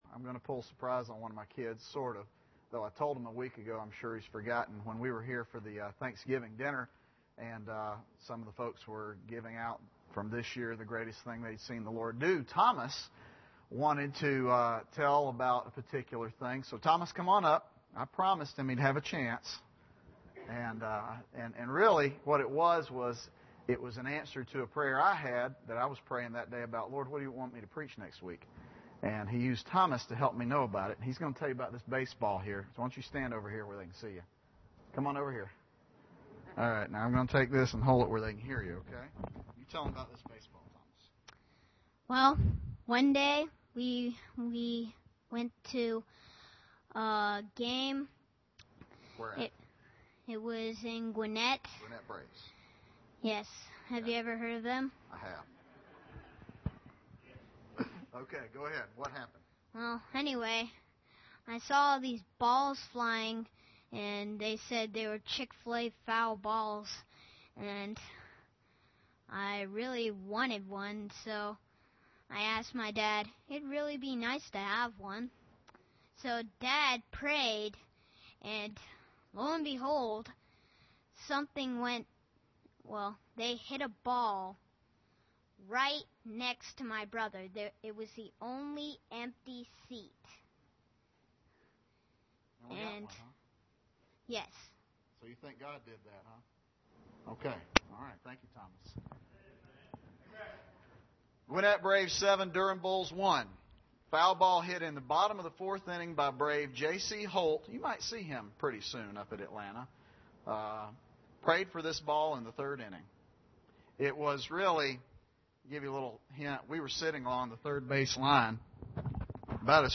Philippians 4:6-7 Service Type: Sunday Evening Bible Text